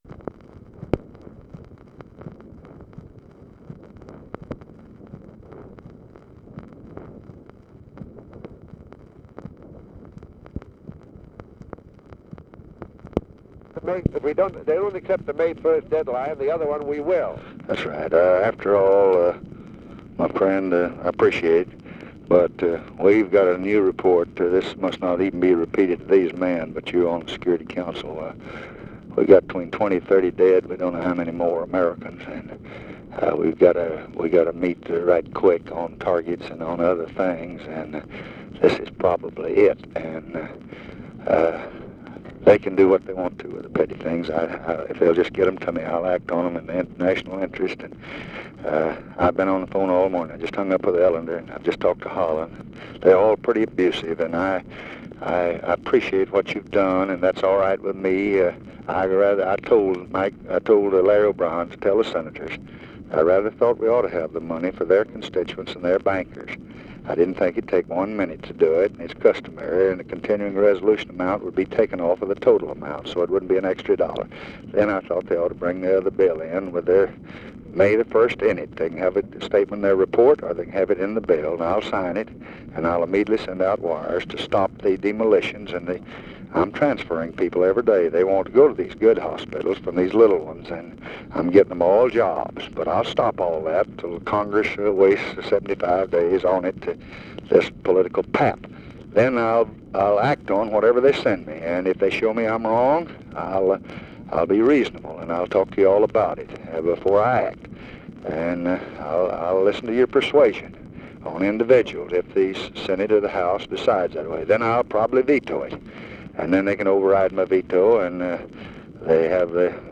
Conversation with JOHN MCCORMACK, February 10, 1965
Secret White House Tapes